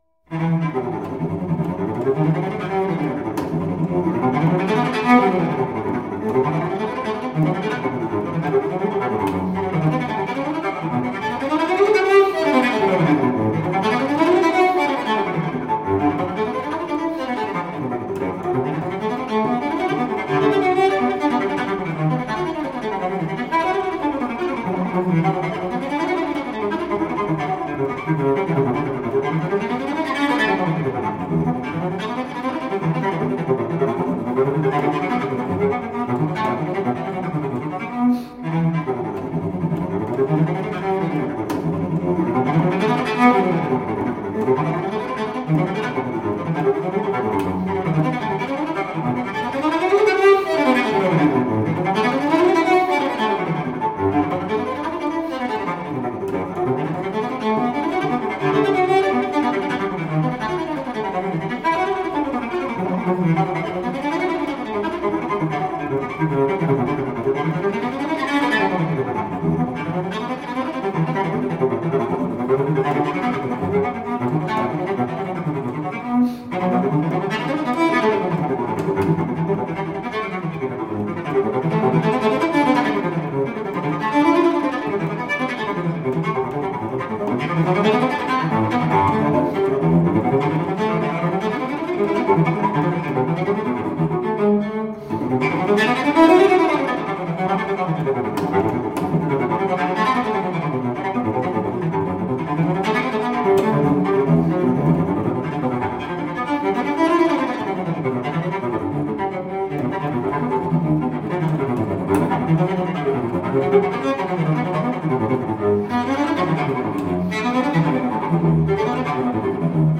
Classical, Baroque, Instrumental, Cello